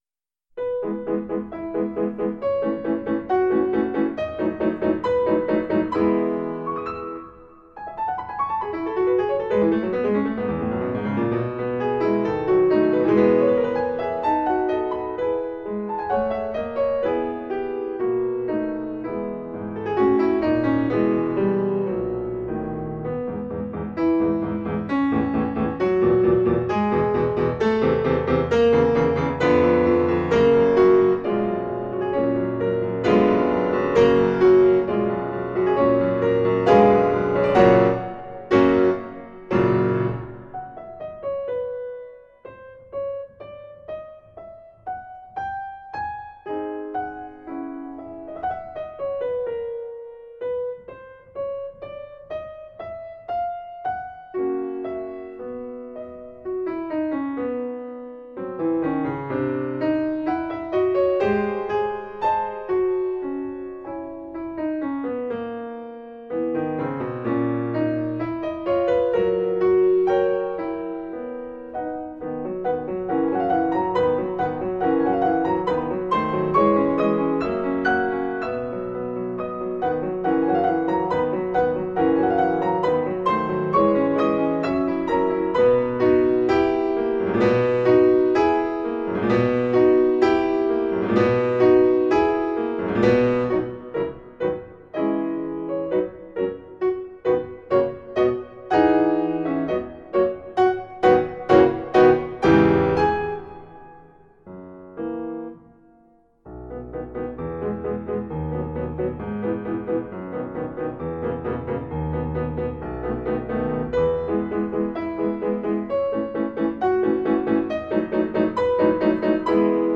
Instrumental Classical, Classical Piano